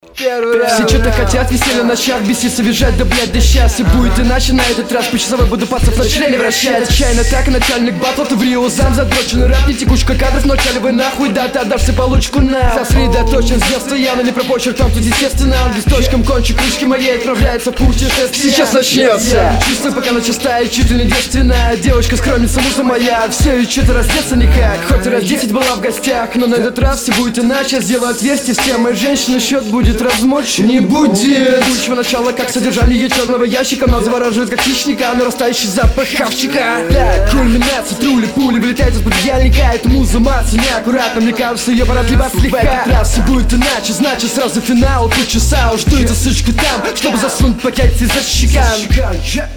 Монотонно слегка, плохо тебя слышно, дикция не идеальная.
На удивление, такое раскрытие на баттле весьма редко, лично мне качество очень испортило впечатление, много слов не понял